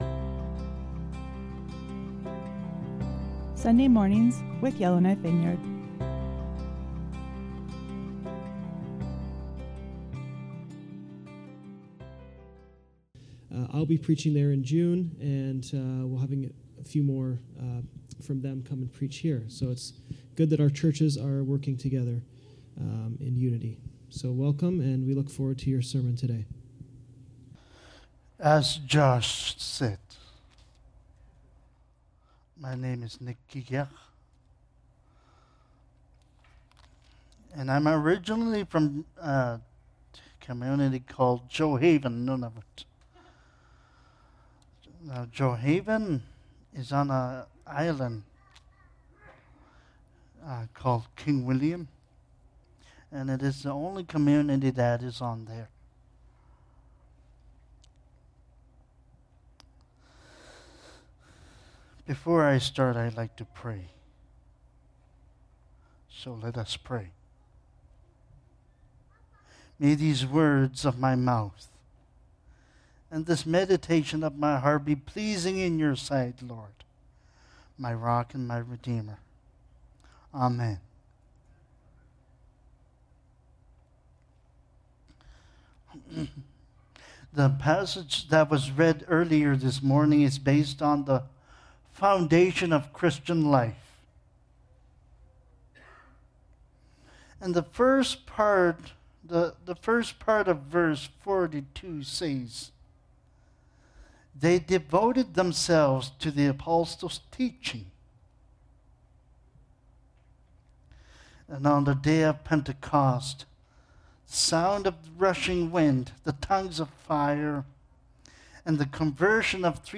Sermons | Yellowknife Vineyard Christian Fellowship